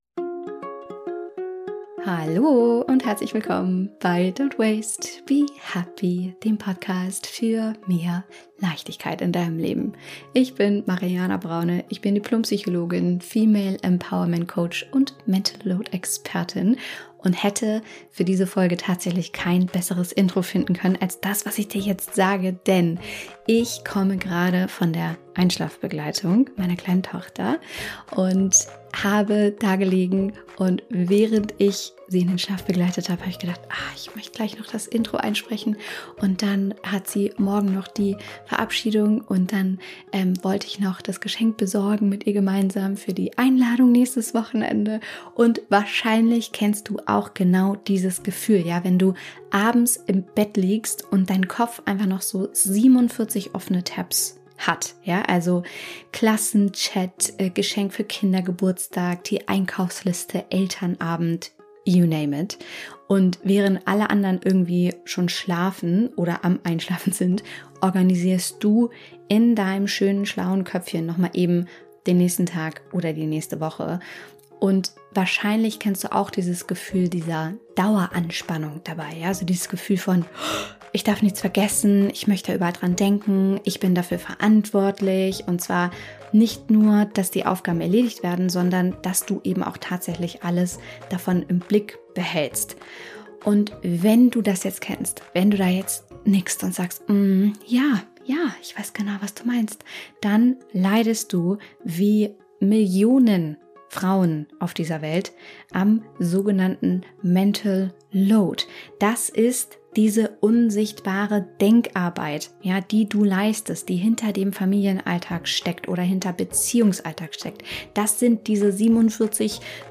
In diesem tiefen, intensiven Gespräch